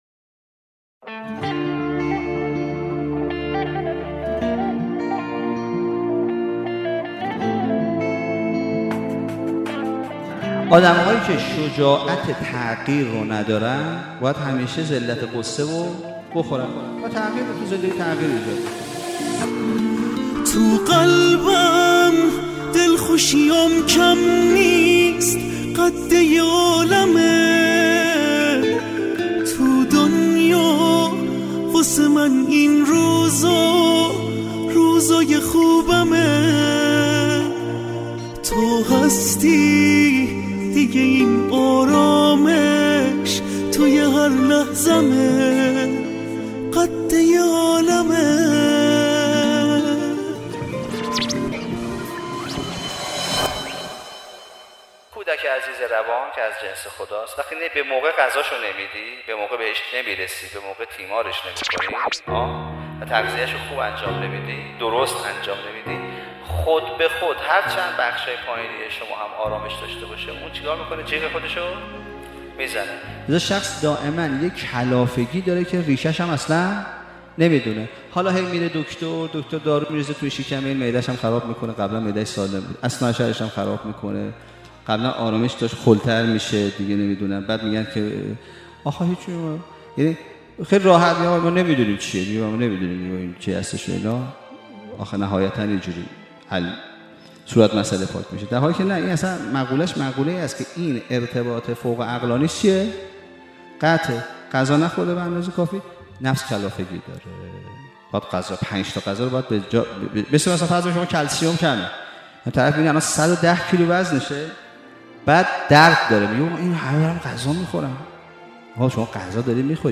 سومین بخش از سلسله مباحث صوتی «سبک زندگی شاد» در بیان دلنشین
سخنرانی